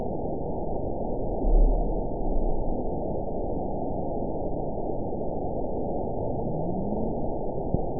event 922562 date 01/31/25 time 01:10:20 GMT (4 months, 2 weeks ago) score 9.24 location TSS-AB04 detected by nrw target species NRW annotations +NRW Spectrogram: Frequency (kHz) vs. Time (s) audio not available .wav